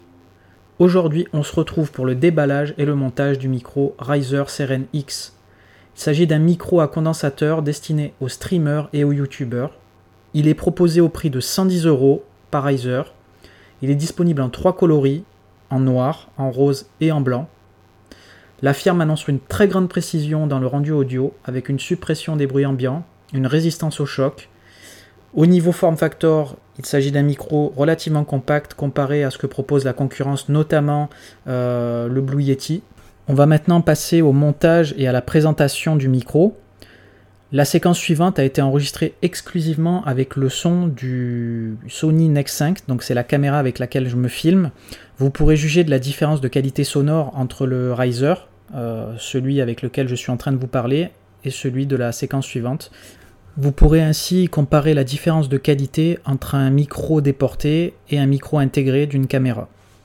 La qualité sonore du Seiren X est très bonne sans être exceptionnelle pour ma part, je dirais qu'il fait le boulot et que les joueurs et autres streamers devraient vraiment bien apprécier ce micro.
En effet lors de mes enregistrements, j'ai pu constater que le timbre de ma voix tirait vraiment trop dans les aigüs, au point que j'avais des difficultés à reconnaître ma voix.
Voici un petit extrait de voix que j'ai pu enregistrer pour vous donner une idée en terme de qualité.